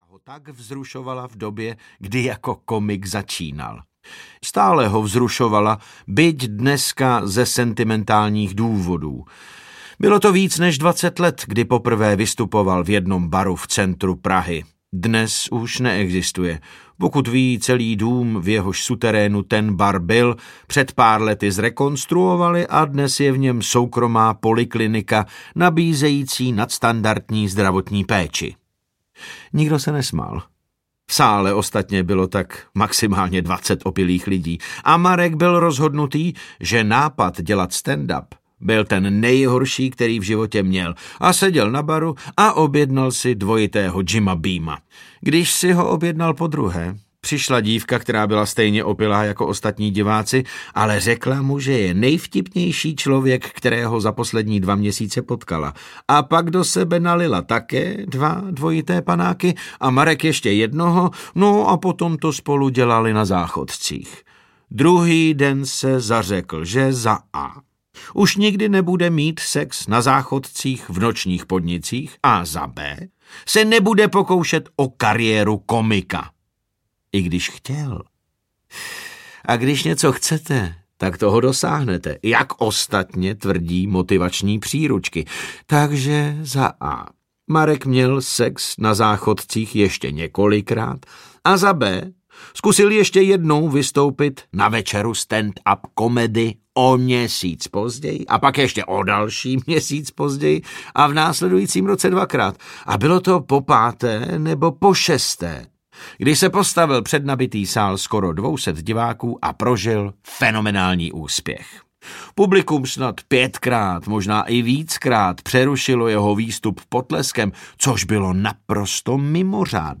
Ukázka z knihy
• InterpretDavid Novotný